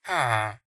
Sound / Minecraft / mob / villager / idle1.ogg